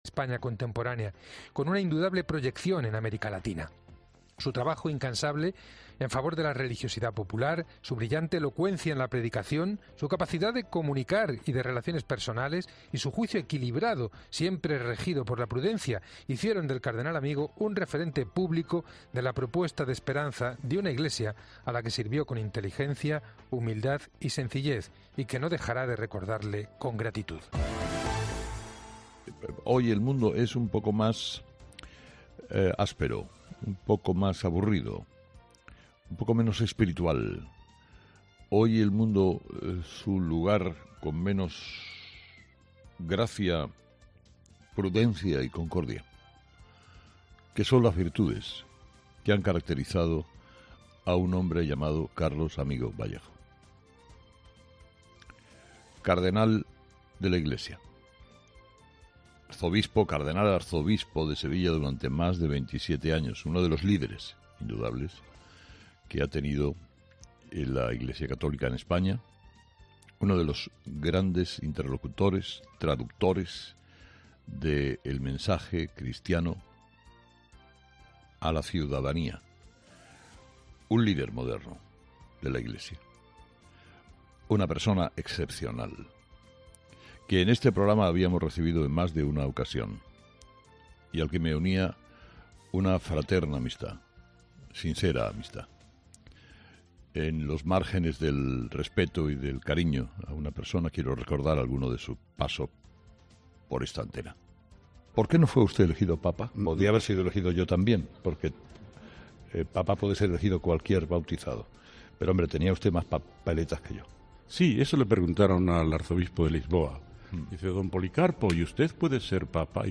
Carlos Herrera, director y presentador de 'Herrera en COPE', ha comenzado el programa de este jueves analizando las principales claves de la jornada, que pasan, entre otros asuntos, por la importante jornada que se va a vivir este jueves en el Congreso de los Diputados y por el fallecimiento del arzobispo emérito de Sevilla, Carlos Amigo.